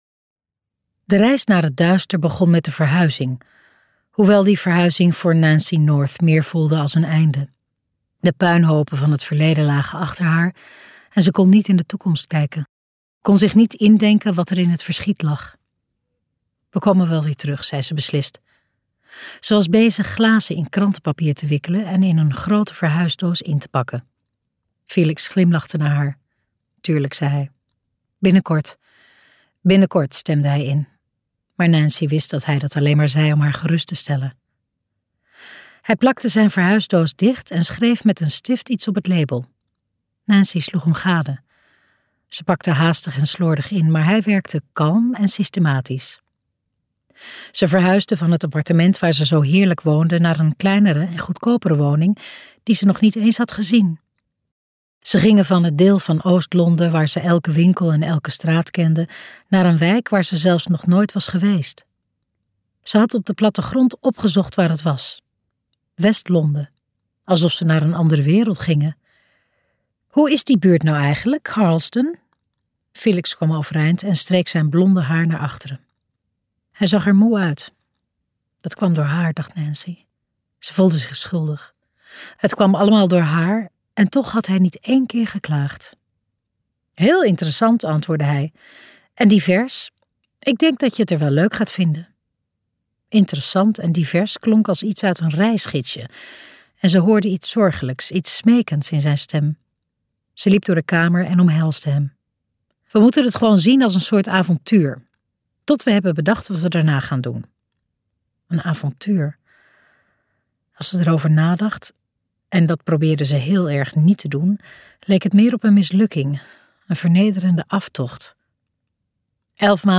Ambo|Anthos uitgevers - Stemmen in het duister luisterboek